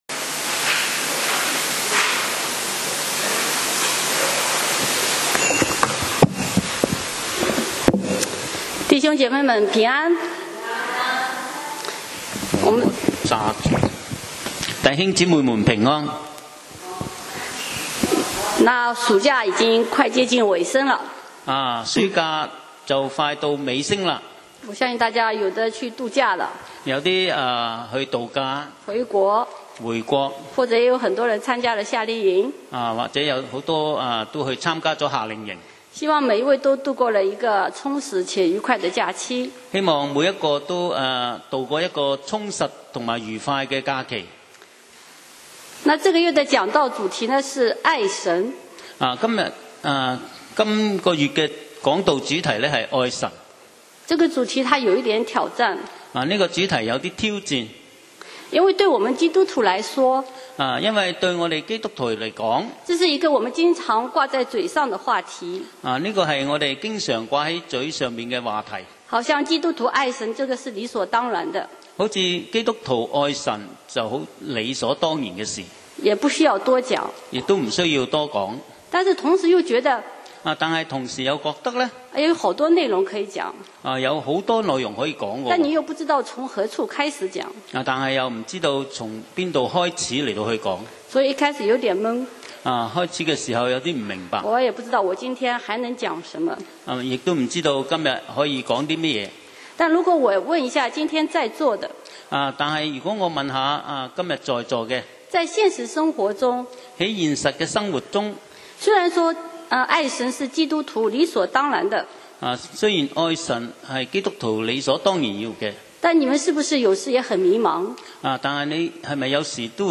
講道 Sermon 題目 Topic：爱不是命令 經文 Verses：约翰一书 4:19 我们爱，因为神先爱我们。